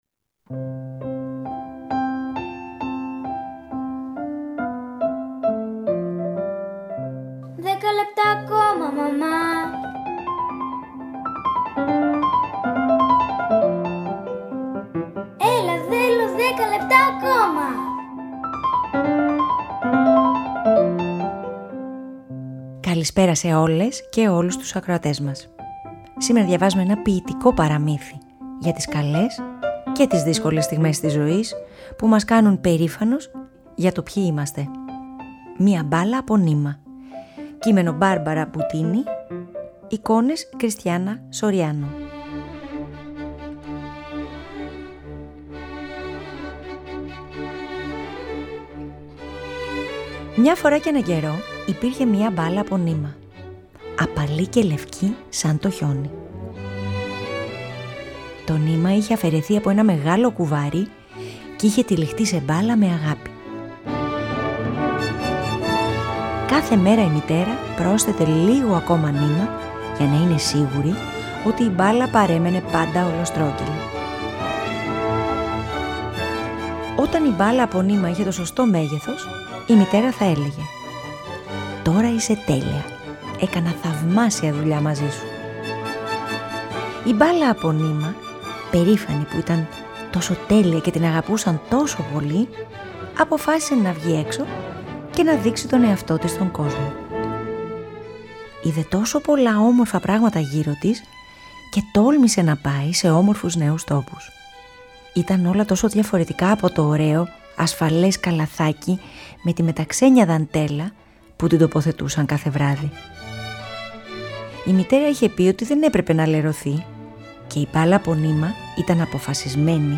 Αφήγηση-Μουσικές επιλογές